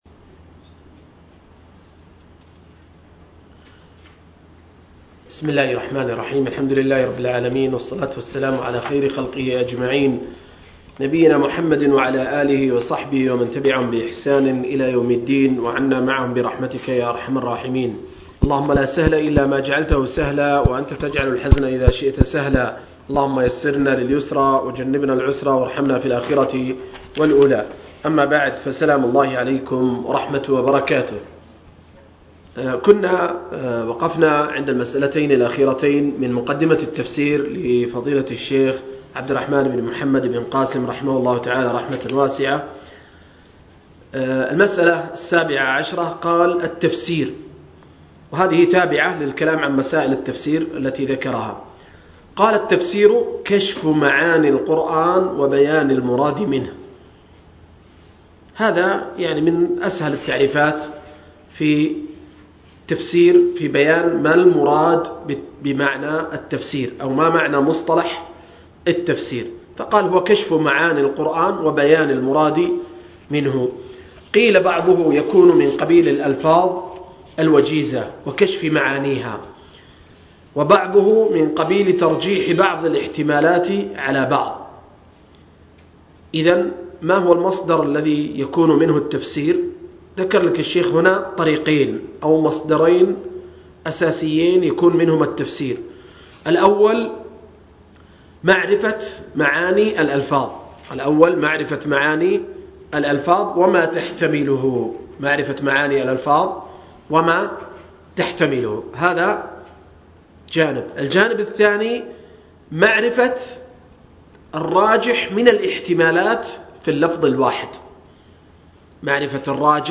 07-مقدمة التفسير للشيخ ابن قاسم رحمه الله – الدرس السابع والأخير